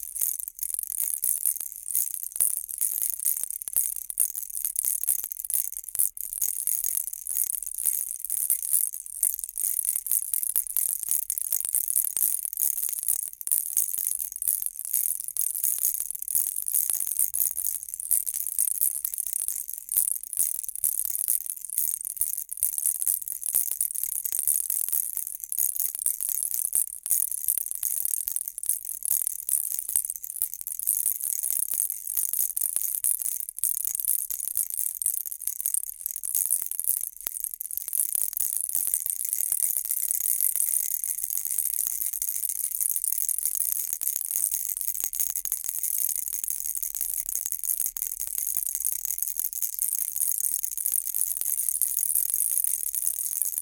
В подборке собраны варианты с разным тембром и интенсивностью звучания: от тихого шуршания до громкого треска.
Трясут погремушку